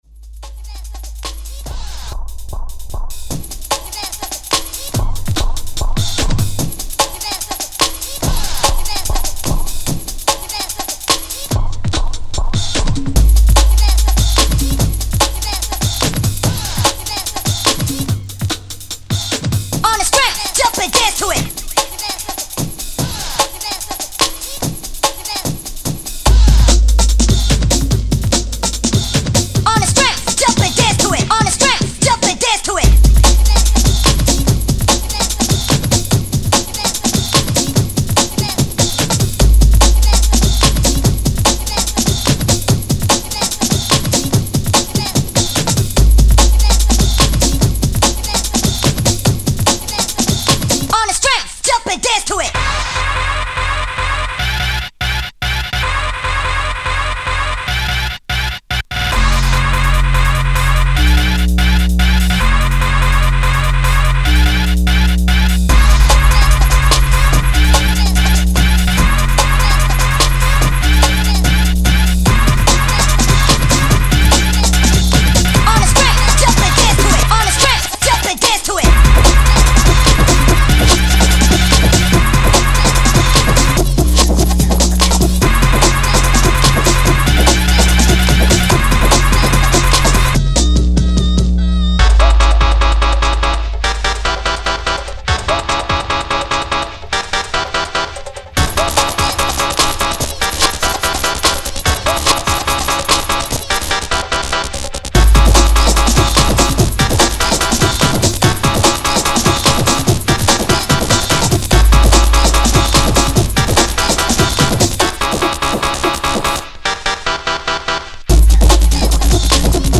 90s Jungle